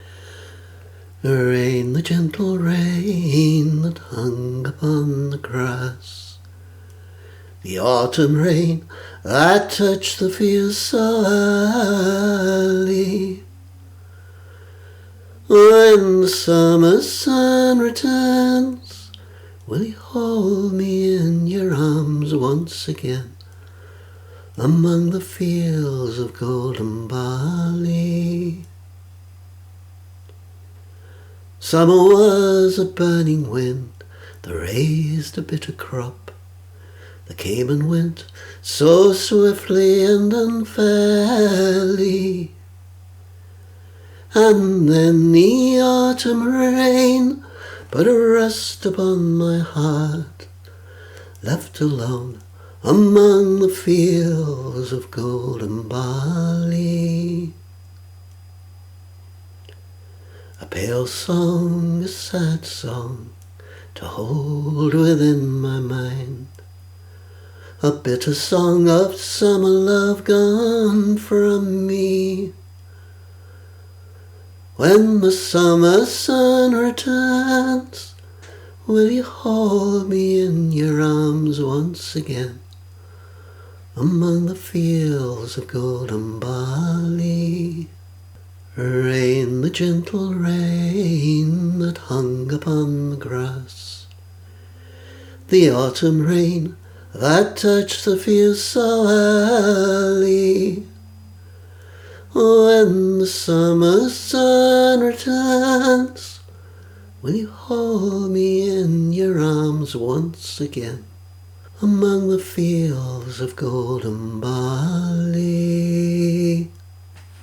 My entry for the July 2020 Trad2Mad competition for unaccompanied singers.
Nowadays, I often sing it with guitar (sometimes using the first verse as a chorus), but I originally intended it to be sung unaccompanied.
Audio capture, mastered to raise the volume slightly: